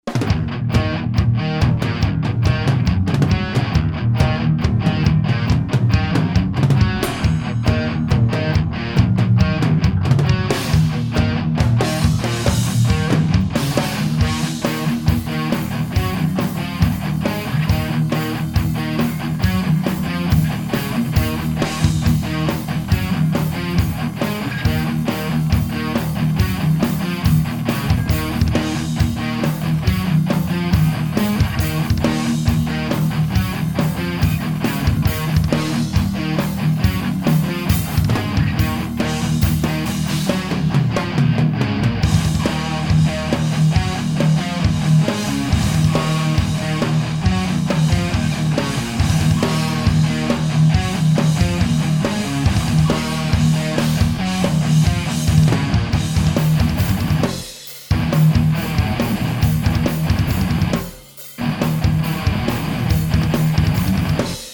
Pre vsetkych priaznivcov internetoveho mudrovania a pocuvania a porovnavania ukazok som pripravil 6 vzoriek kvazy toho isteho - 2 beglajtove gitary + basa + bicie. Rozdiel je iba v gitarach, boli pouzite viacere gitarove aparaty alebo modeling.